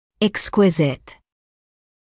[ɪk’skwɪzɪt], [‘ekskwɪzɪt][ик’скуизит], [‘экскуизит]тонкий (о деталях); полный, совершенный